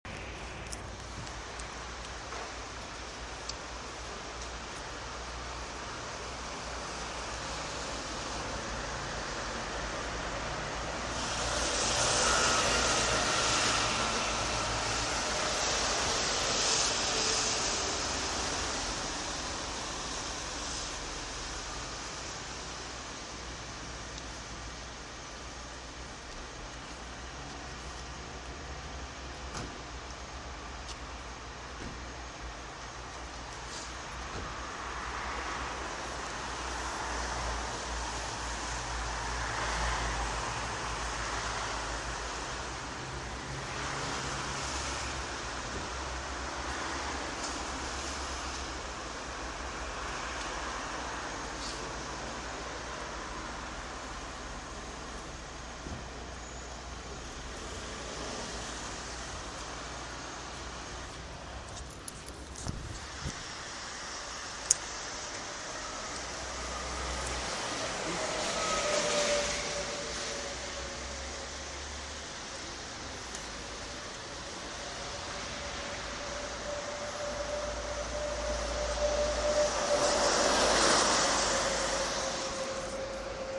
Bruits de voitures, coiffeur, et bus